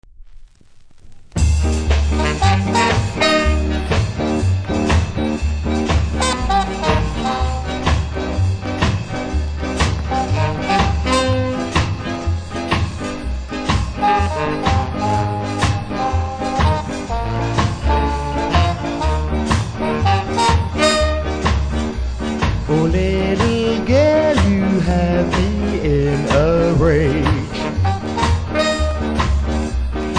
プレス時に白い異物混入しており後半１発だけノイズあり。